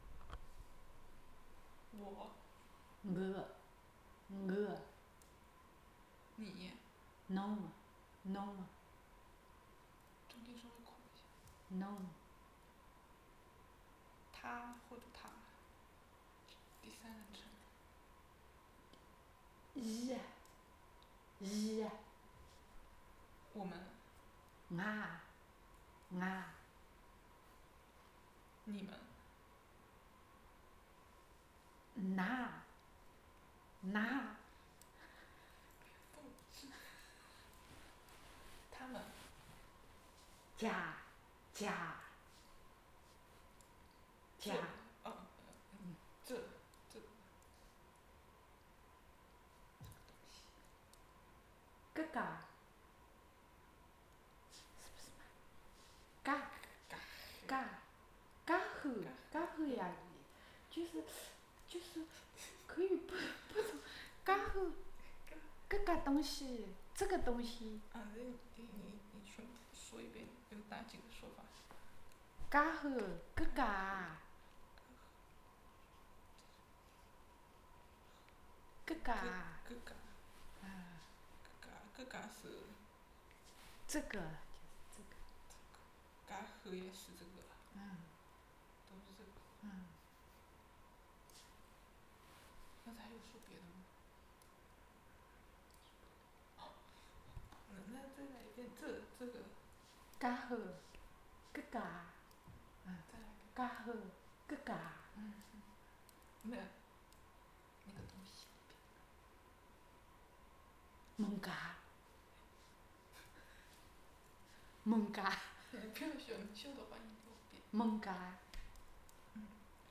This is an elicitation session on the pronouns in the Swadesh list.
digital wav file recorded at 44.1 kHz/16 bit on Zoom H4n Pro recorder
Xinchang, Zhejiang Province, China; recording made in USA